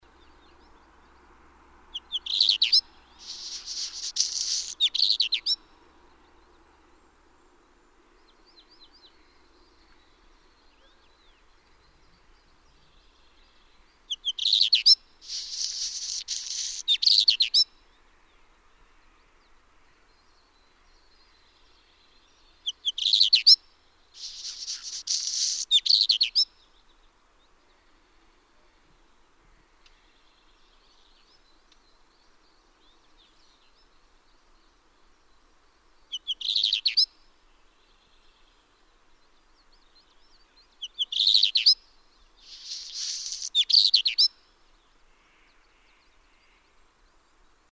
Eastern Black Redstarts Phoenicurus ochruros phoenicuroides in Altai
The following photos and sound recordings are all taken close to Aktash, Altai Republic, Russia, 24 or 25 June 2010. The area was 1400-1500 m asl, with alternating conifer forest, rivers and rocky cliffs.
Song We heard song only from male-plumaged birds.